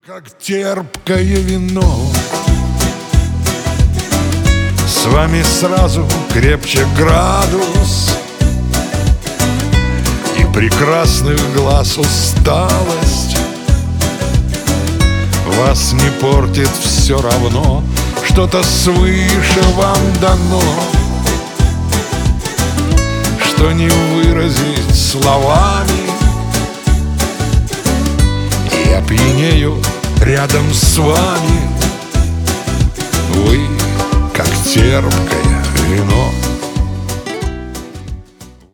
• Качество: 320, Stereo
мужской голос
спокойные